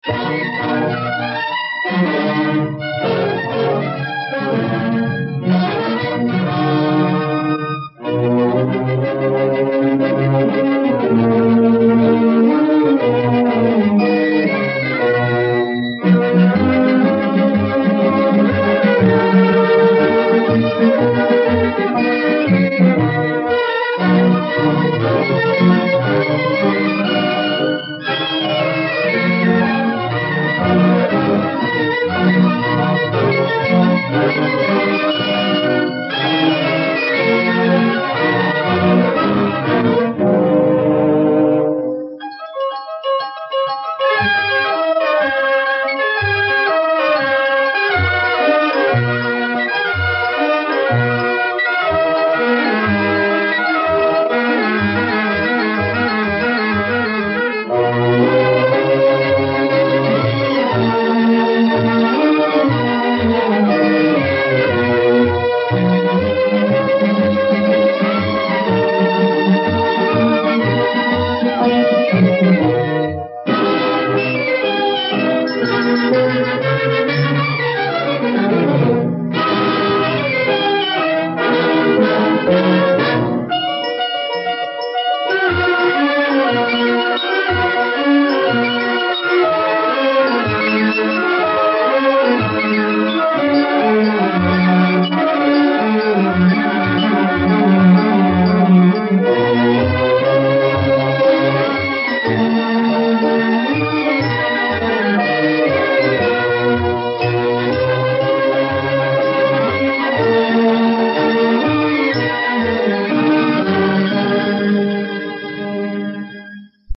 Title Music